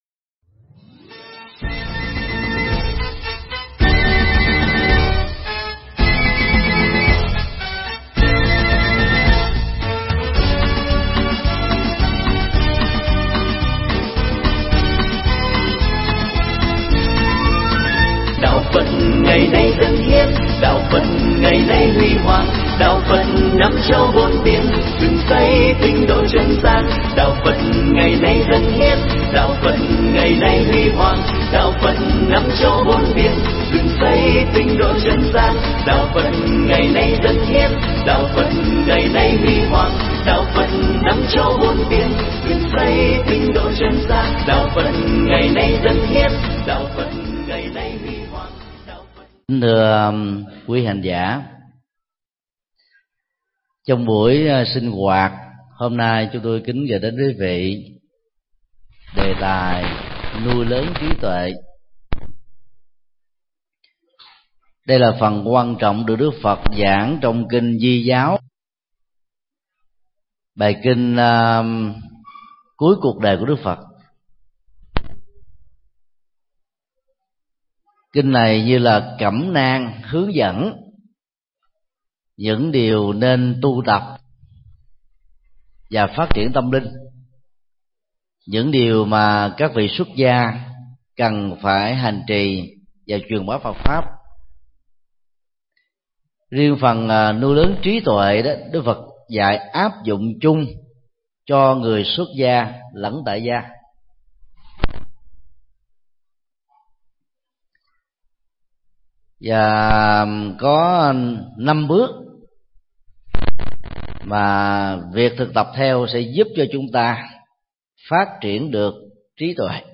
Tải mp3 Pháp thoại Kinh Di Giáo 08